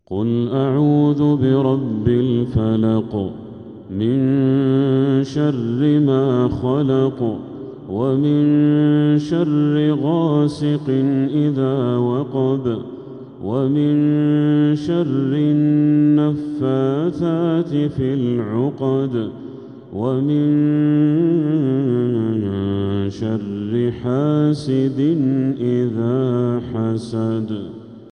من الحرم المكي